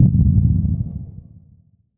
Index of /musicradar/impact-samples/Low End
Low End 01.wav